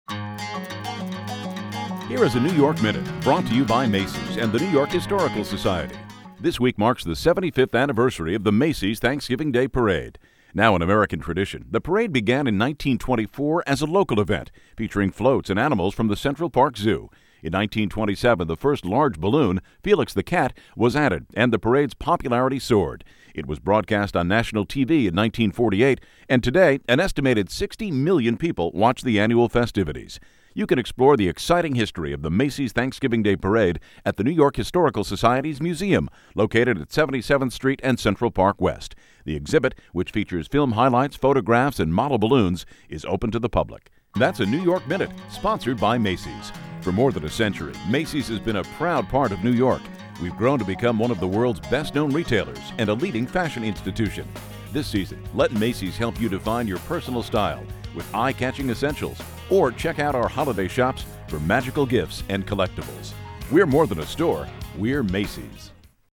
Here are examples of radio scripts we wrote and produced for minute-long spots.